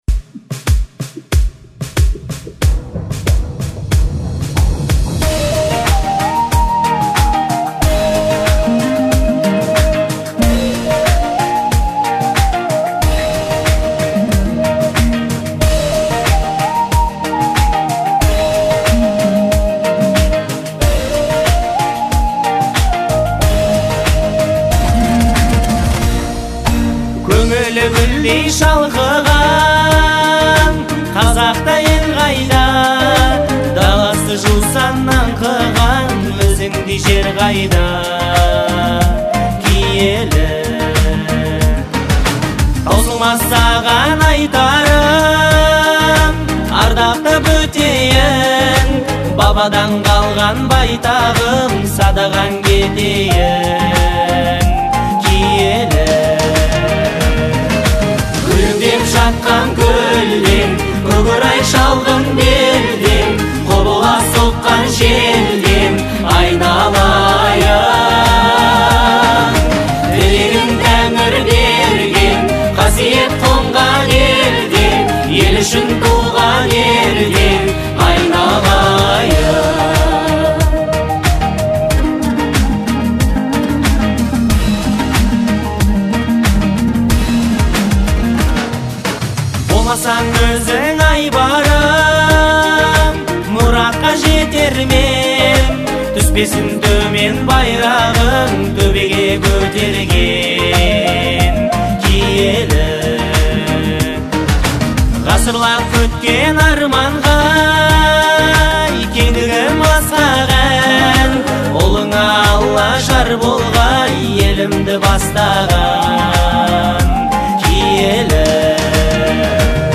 Казахские песни